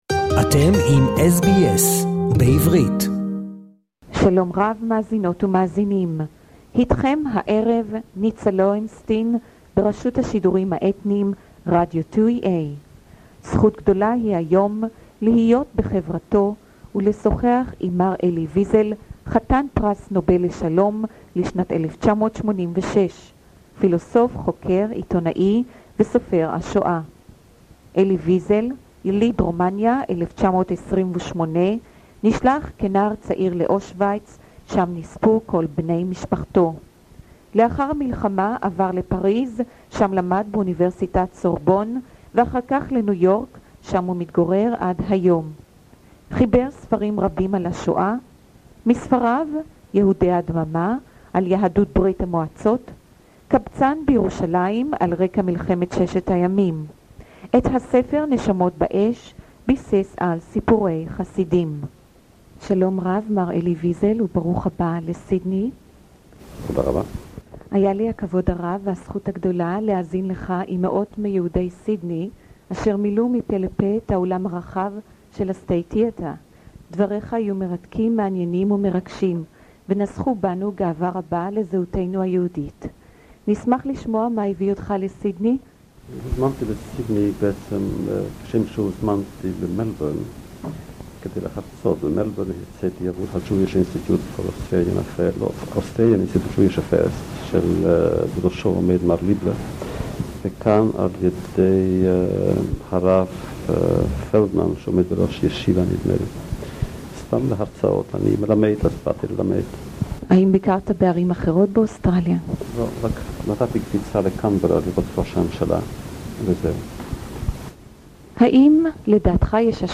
I met him at the hotel he was staying in Bondi and used what I call now, primitive, and low-quality recording equipment that was available to me at the time.